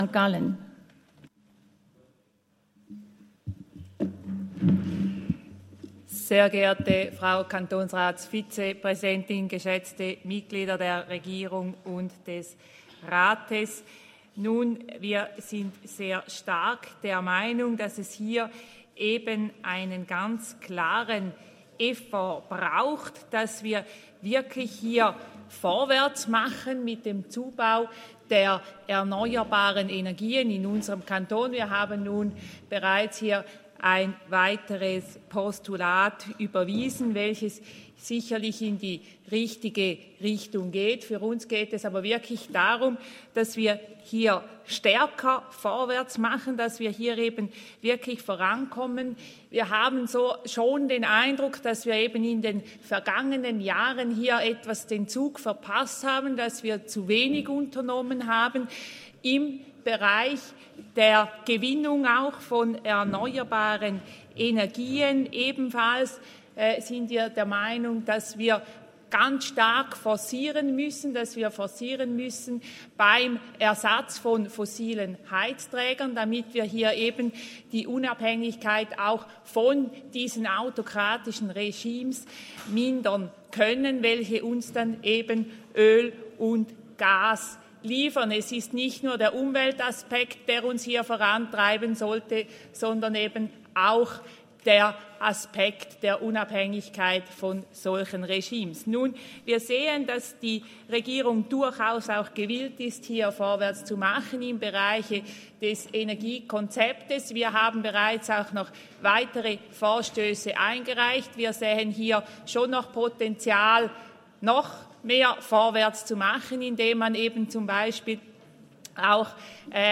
20.9.2022Wortmeldung
Session des Kantonsrates vom 19. bis 21. September 2022